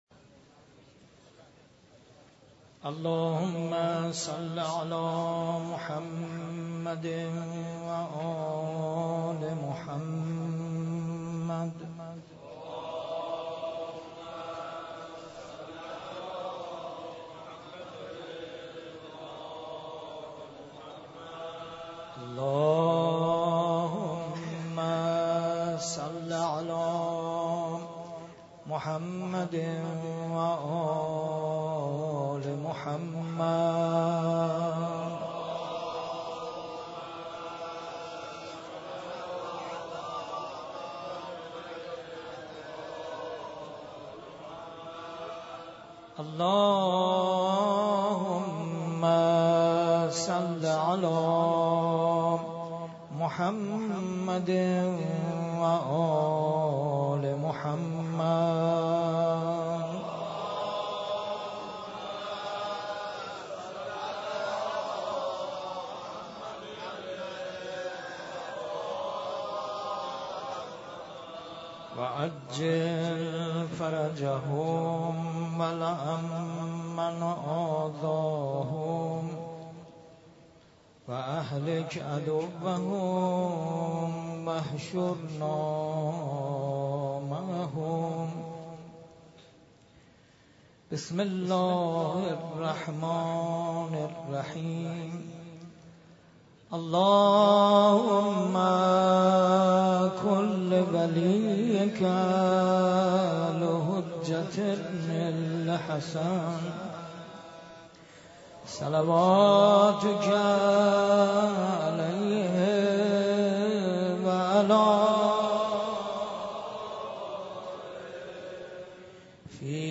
مراسم شب 19 , 21 ماه رمضان
در مسجد شهدا برگزار گردید
قرائت دعای ابوحمزه (قسمت دوم) و مراسم احیاء ، روضه حضرت امام علی (علیه السلام)